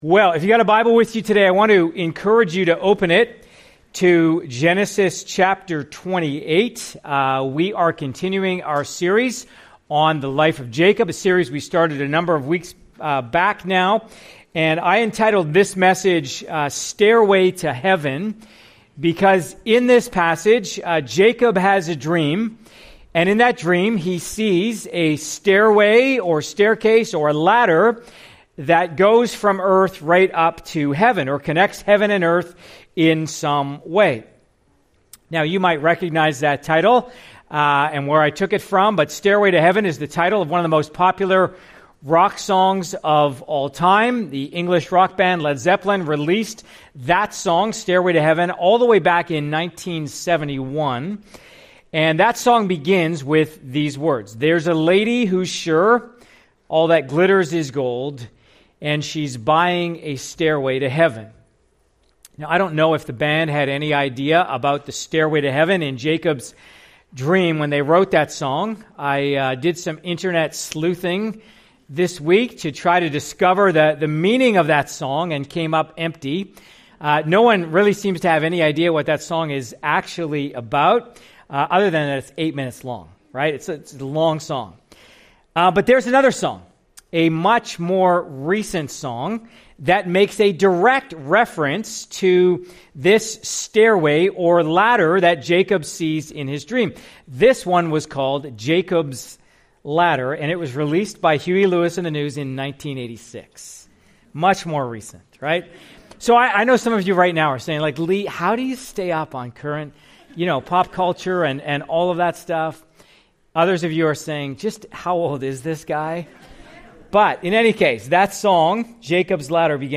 Part of our series, “ Straight Lines with Crooked Sticks ,” following the life of Jacob in the book of Genesis. CLICK HERE for other sermons from this series.